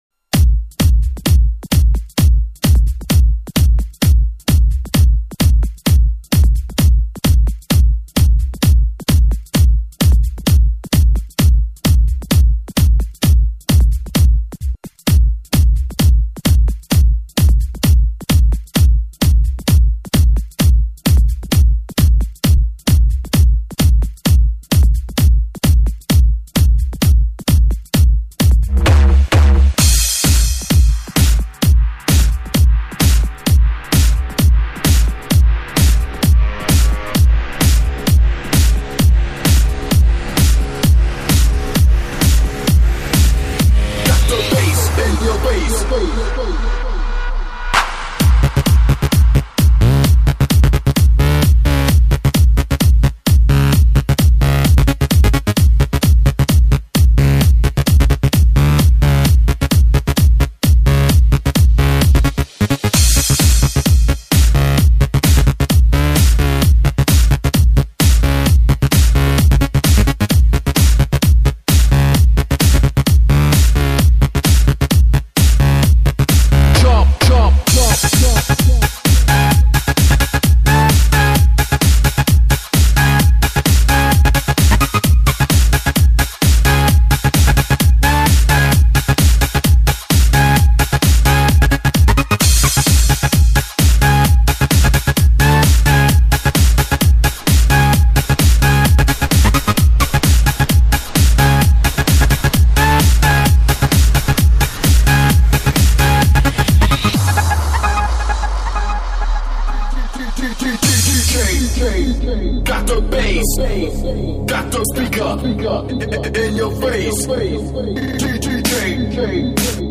Жанр:Новогодний/Позитивный/Electro/House